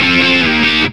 GTR ROCKC0JR.wav